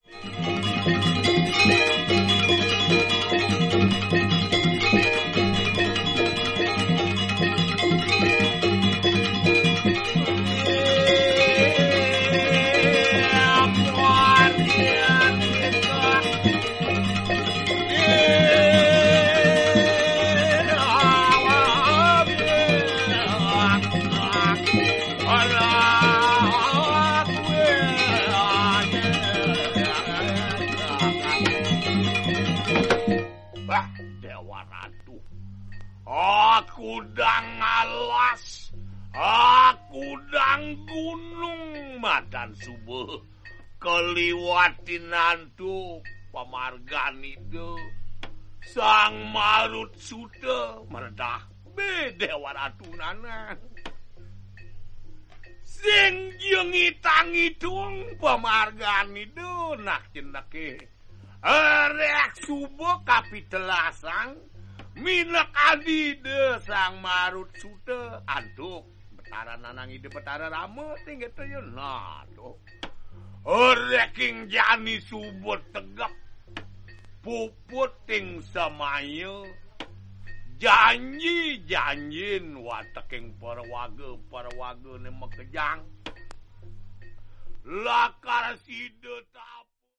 バリの伝統的古典音楽をコンパイルしたオムニバス。
Wayang Kulit